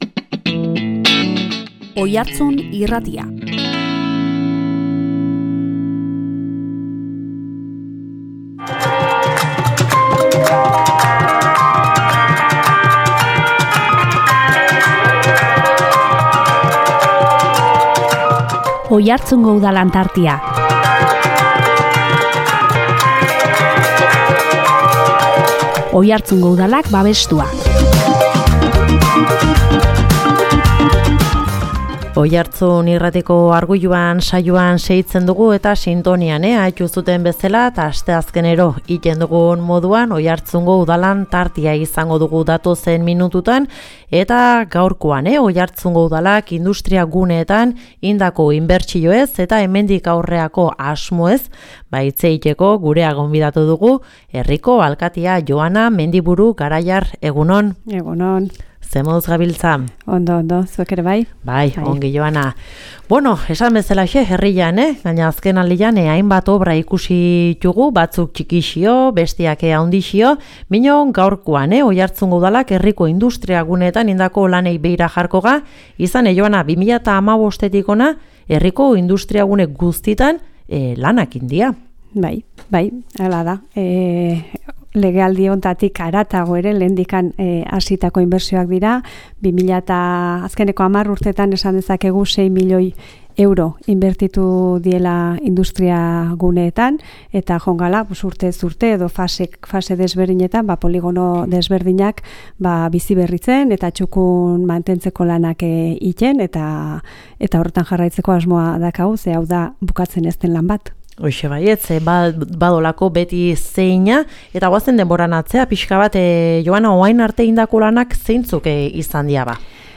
Azken urteetan industriaguneetan egin diren inbertsioez eta hemendik aurrerako asmo nagusiez hitz egitera gerturatu da Joana Mendiburu Garaiar herriko alkatea.